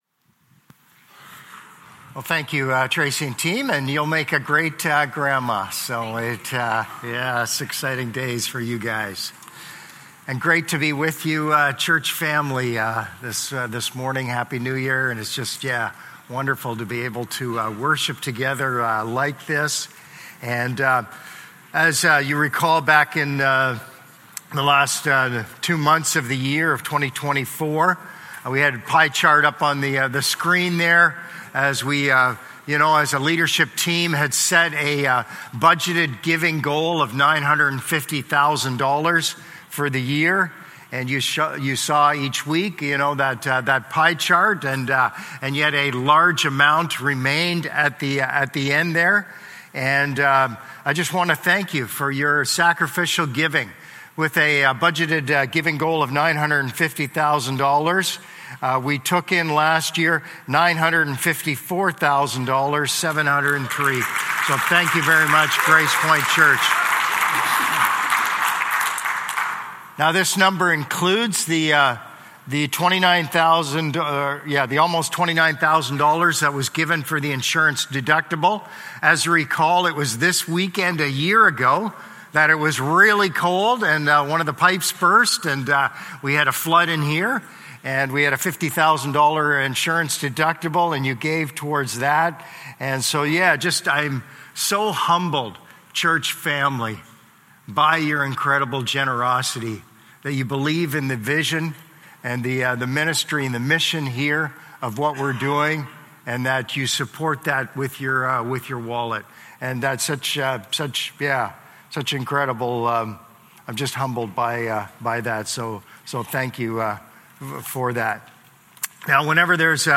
Sermons | Gracepoint Community Church